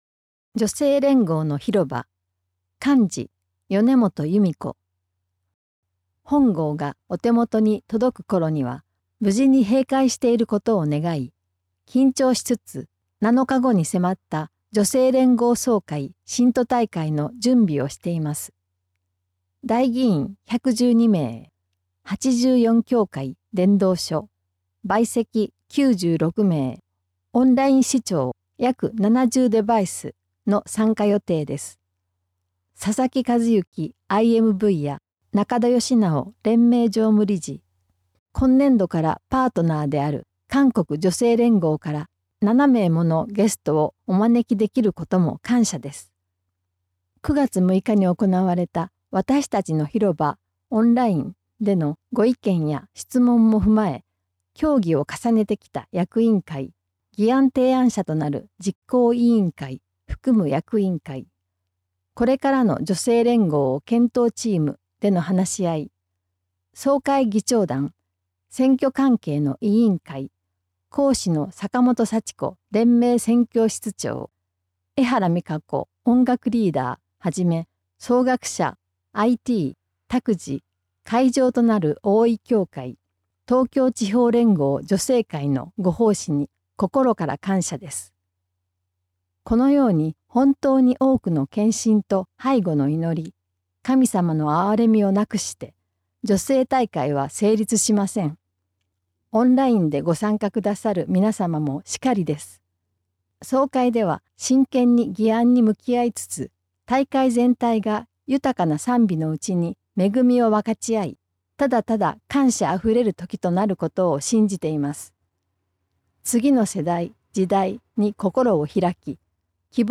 2025年４月号から『世の光』Webの各記事から、朗読者による音声（オーディオ）配信がスタートしました。